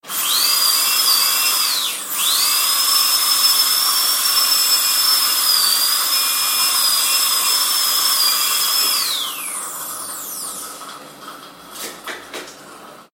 Атмосферные звуки бормашины и слюнеотсоса